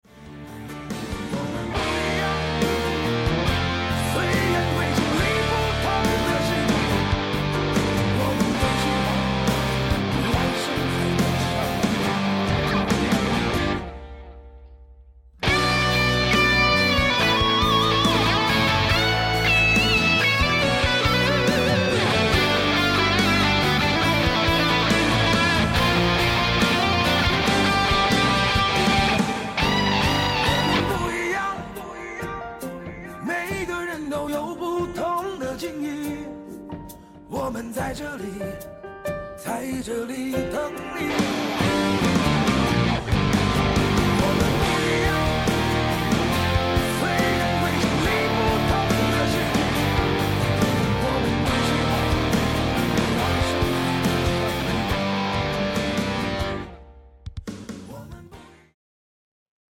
First time with Gibson Les Paul
𝗚𝘂𝗶𝘁𝗮𝗿 𝗦𝗼𝗹𝗼 𝗖𝗼𝘃𝗲𝗿